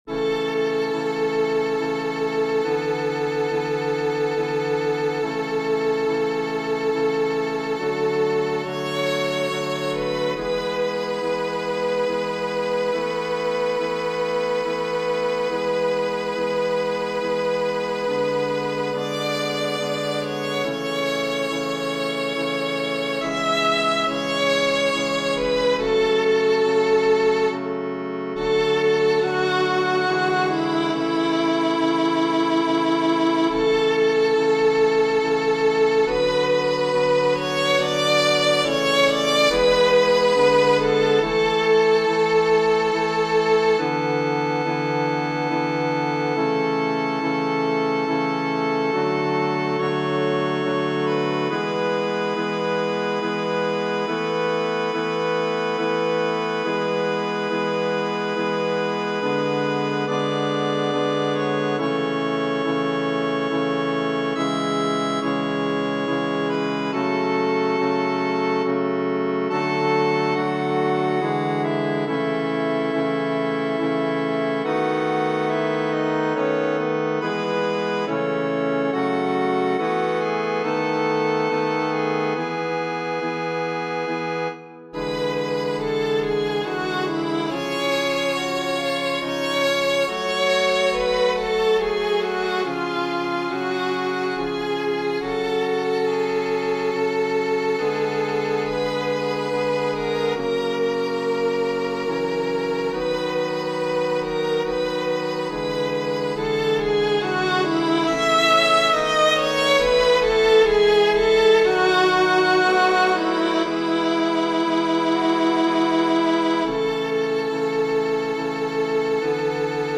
Genere: Religiose
"Dank sei Dir, Herr", in italiano "Grazie a Te, Signore", è un'aria che ancora oggi viene spesso attribuita a Georg Friedrich Haendel.